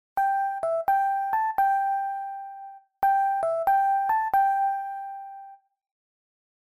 알림음 8_준비됐나요4.mp3